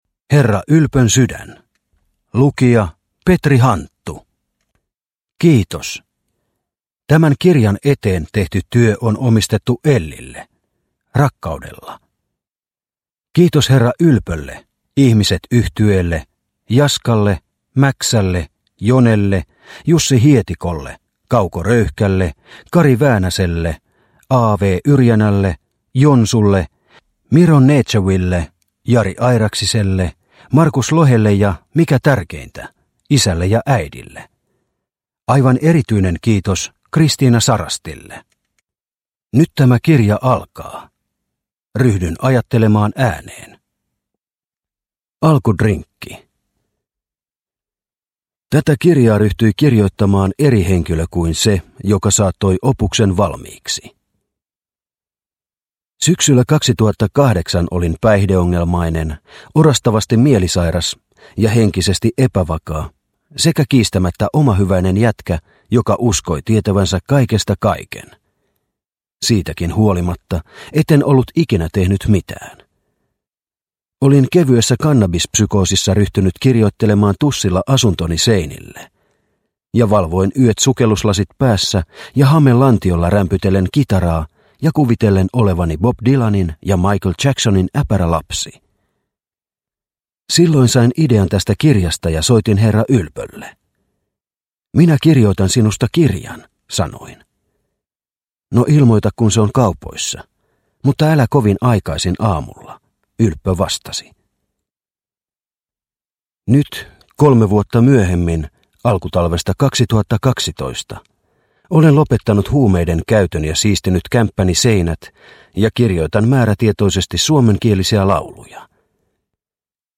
Herra Ylpön sydän (mp3) – Ljudbok – Laddas ner